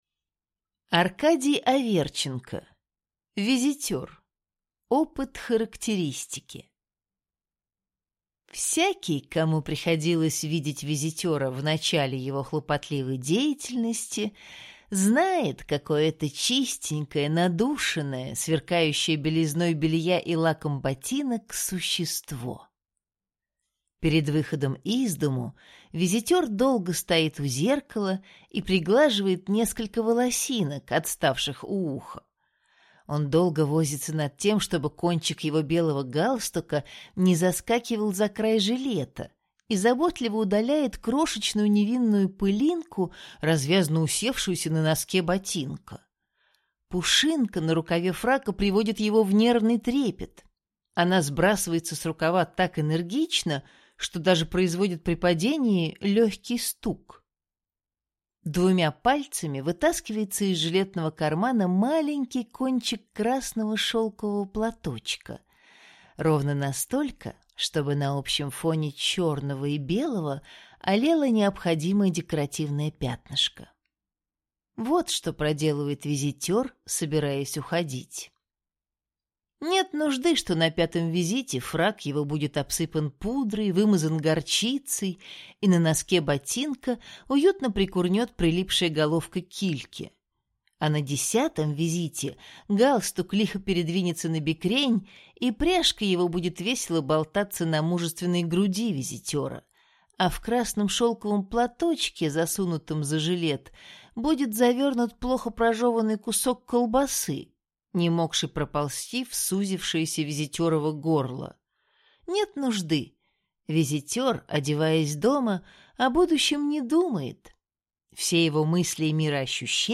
Aудиокнига Визитер